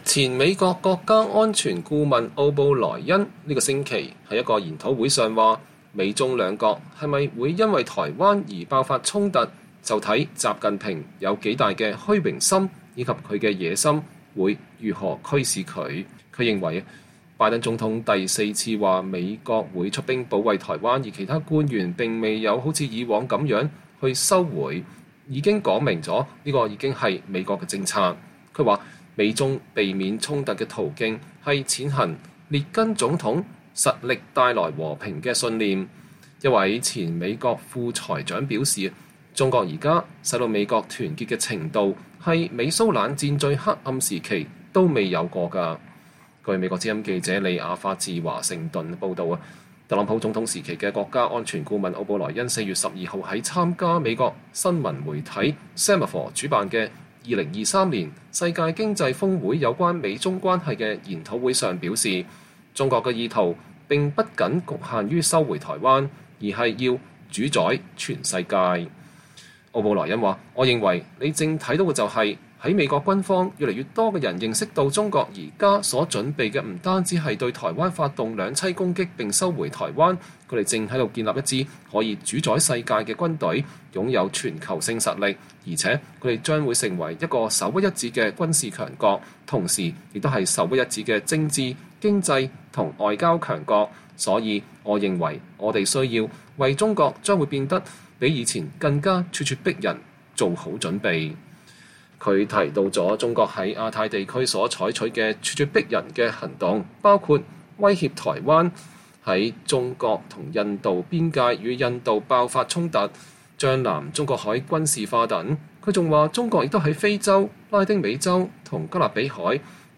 2023年4月12日，前美國國家安全顧問奧布萊恩(Robert O’Brien)參加Semafor主辦的2023世界經濟峰會，就美中關係發表看法。